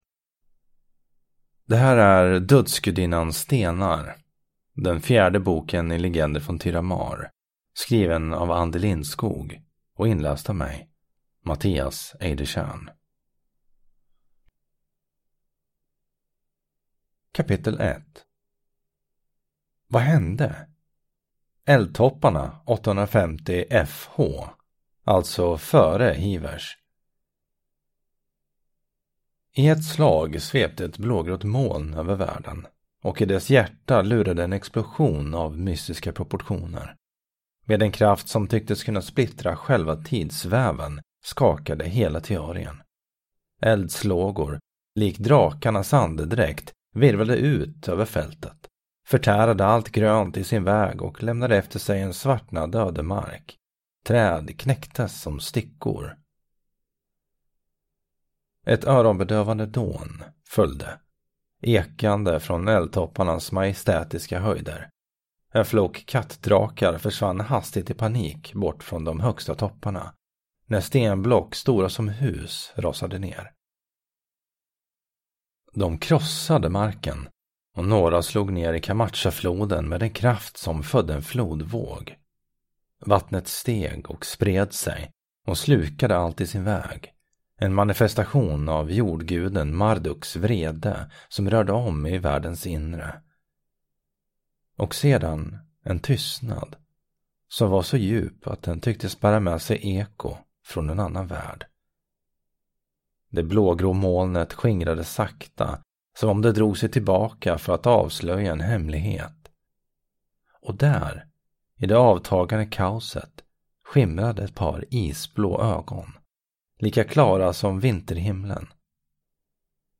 Dödsgudinnans stenar – Ljudbok